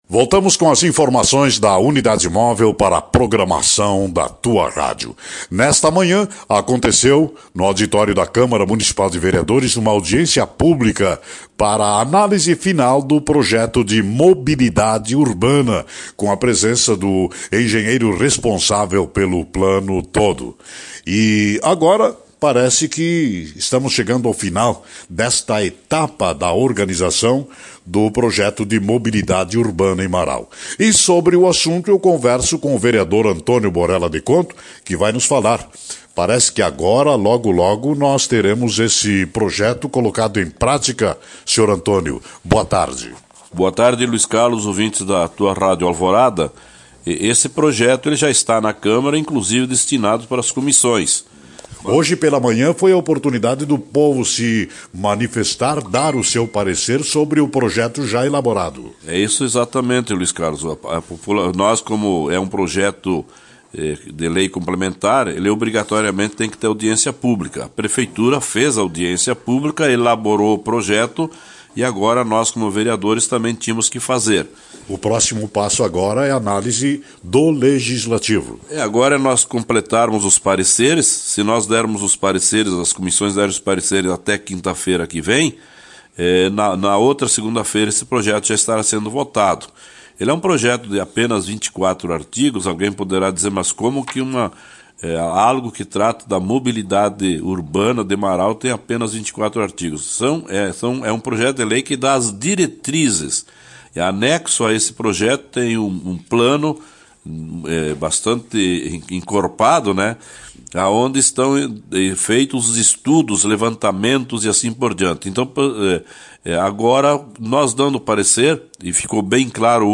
Ouça a entrevista completa com Antônio Borela no áudio da matéria.